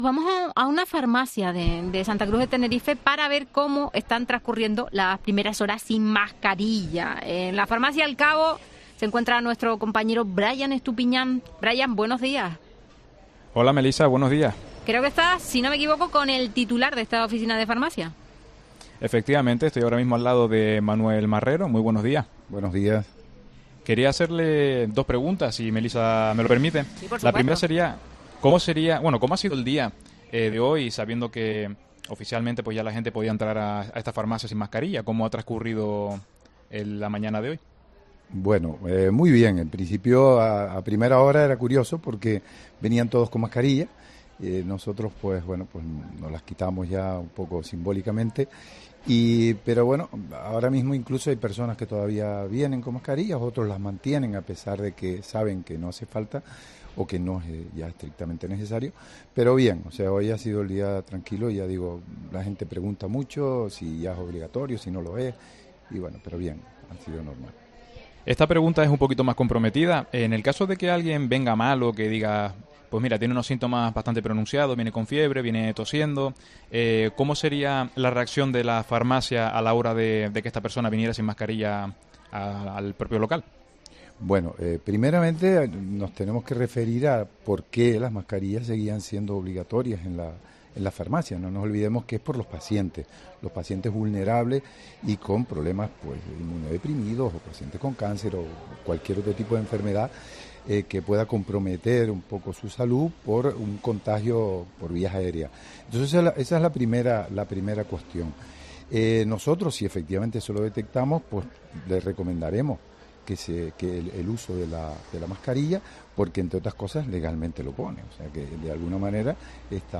Esto es lo que opina un farmacéutico y la ciudadanía de Tenerife sobre el fin del uso de la mascarilla